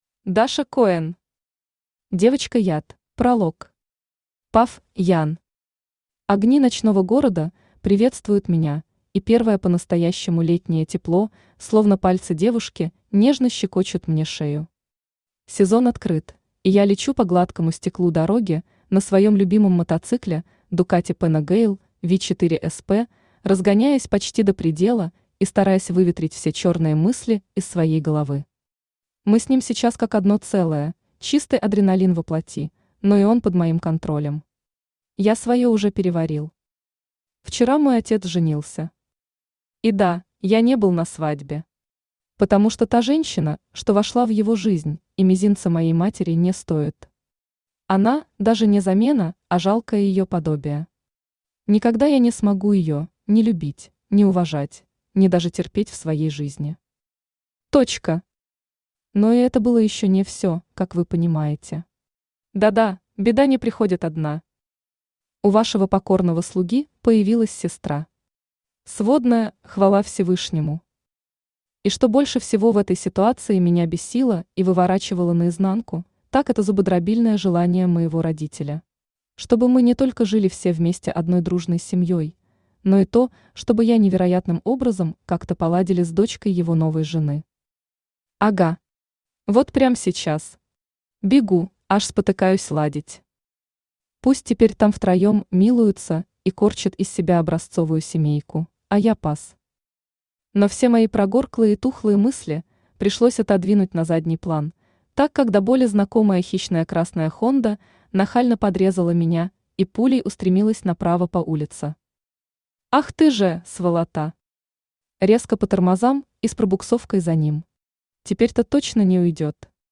Аудиокнига Девочка-яд | Библиотека аудиокниг
Aудиокнига Девочка-яд Автор Даша Коэн Читает аудиокнигу Авточтец ЛитРес.